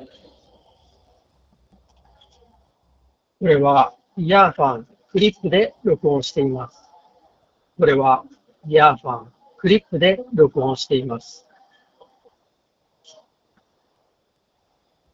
通話時のノイズキャンセリング
スピーカーから雑踏音をそこそこ大きなボリュームで流しながらマイクで収録した音声がこちら。
ノイキャン効果がかなり優秀。
マイクも若干こもり気味なものの悪くはないので、仕事でのちょっとした打ち合わせ程度であれば十分使えると思います。
earfun-clip-voice.m4a